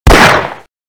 Download Half-life 9mm Pistol sound effect for free.
Half-life 9mm Pistol